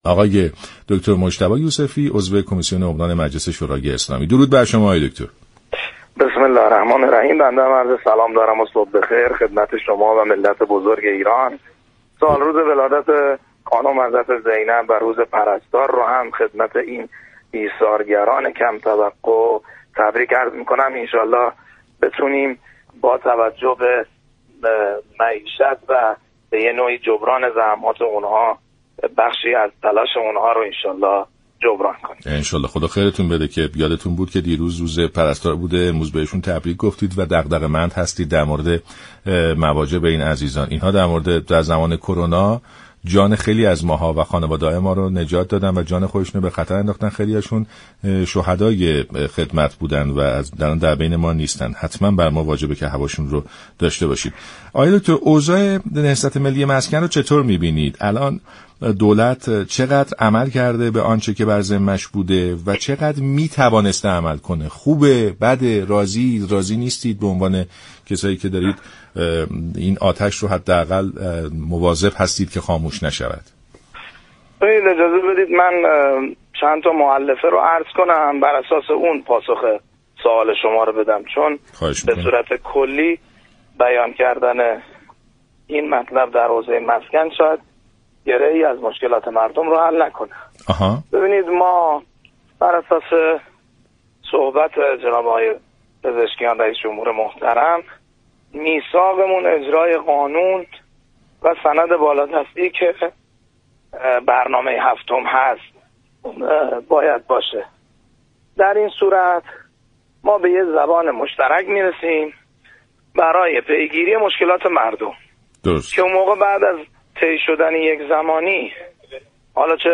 مجتبی یوسفی عضو كمیسیون عمران مجلس گفت: این كار اشتباهی‌ست كه هزینه‌ها بر حسب دلار و ارز تعیین می‌شود و دریافتی و درآمدها را بر اساس ریال.